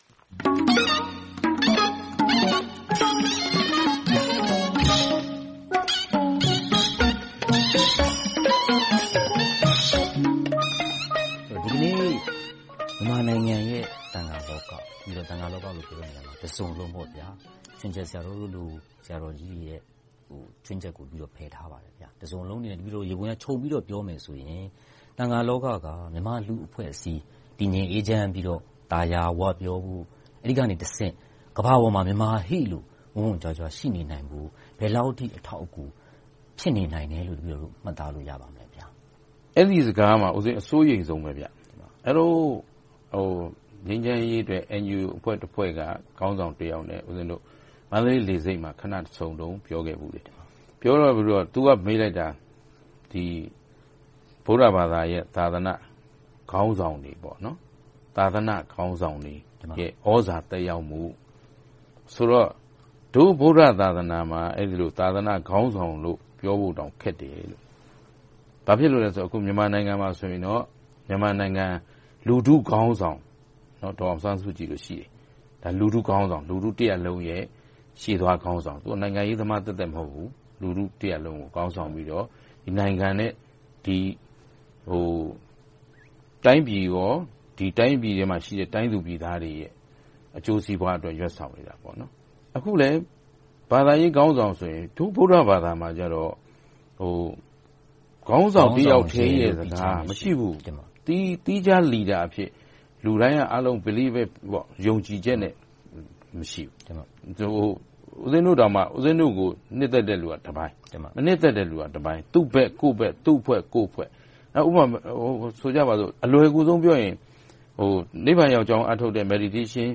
လျှောက်ထားမေးမြန်းခန်း